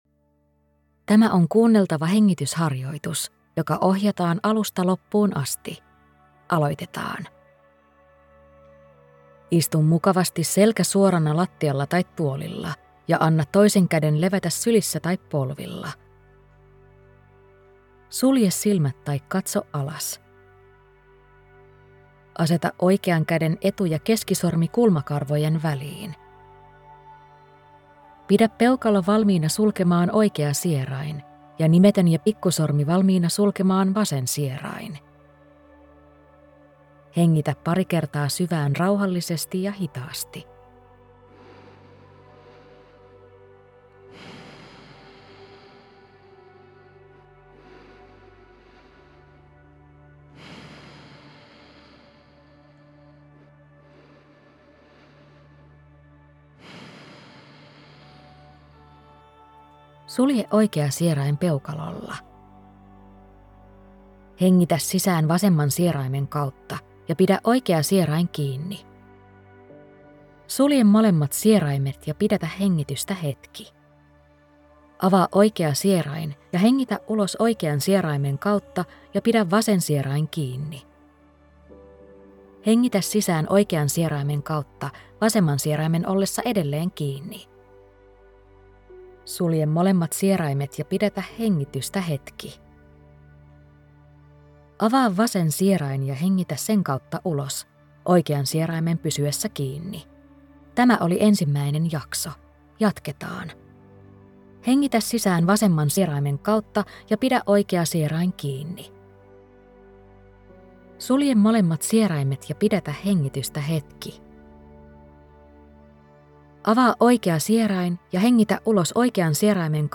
Vuorosierainhengitys – kuunneltava hengitysharjoitus
• Ohjelmassa tehdään 5 hengitystä, jotka kaikki opastetaan.
• Lopussa on 1 minuutti, jonka aikana tunnetaan, miten hengitysharjoitus vaikuttaa.